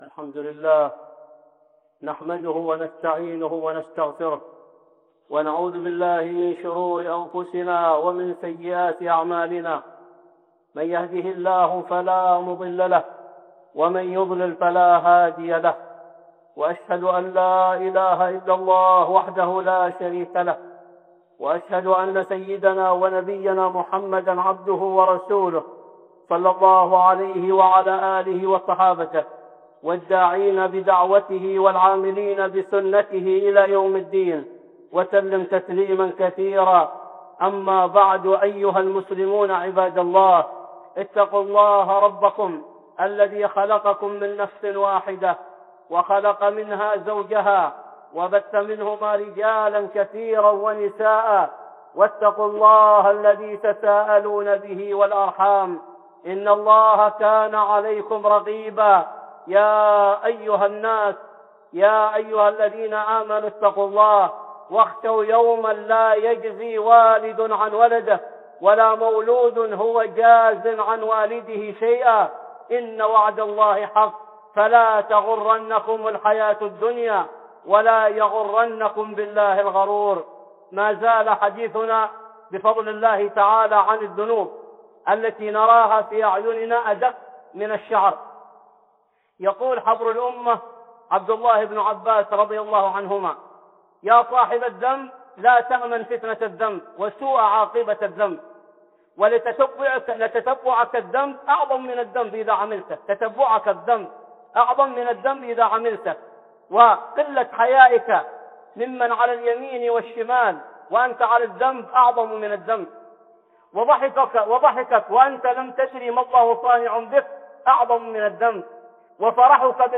(خطبة جمعة) ذنوب نراها ادق من الشعر 2